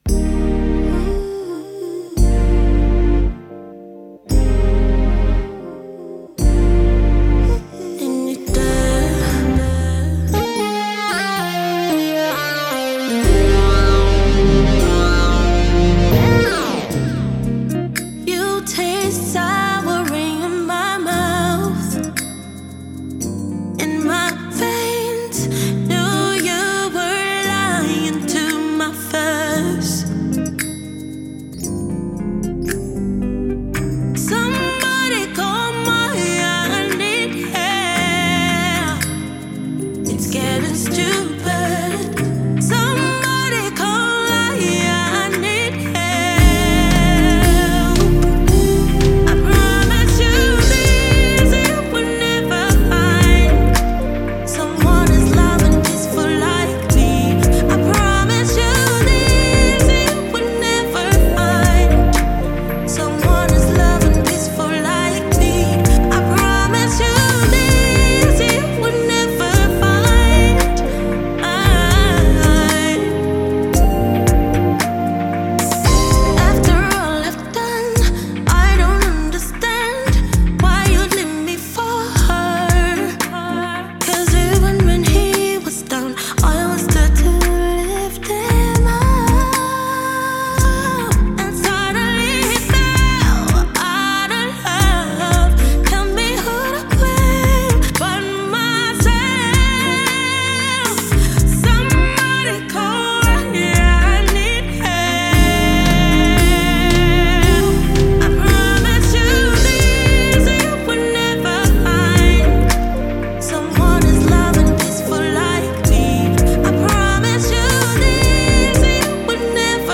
Classical Love Song
Showing off her powerful voice